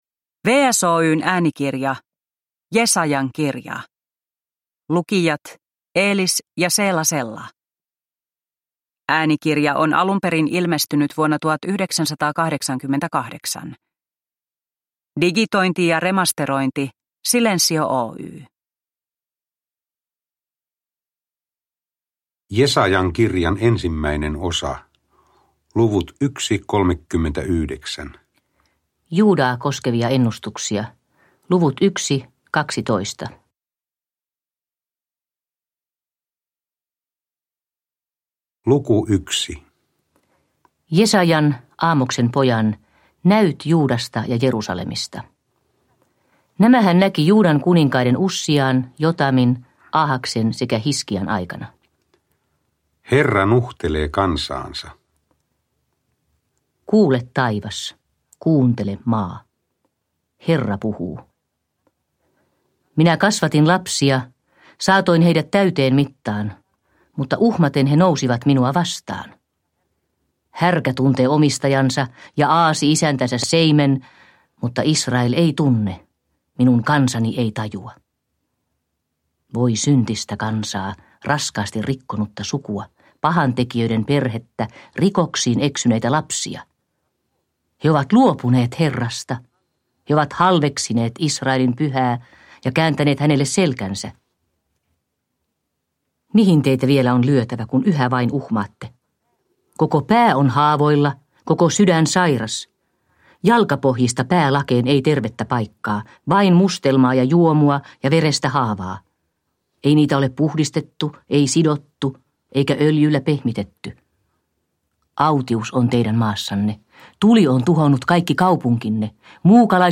Jesajan kirja Seela ja Elis Sellan lukemana.
Äänikirja on nauhoitettu vuonna 1988.
Uppläsare: Seela Sella, Elis Sella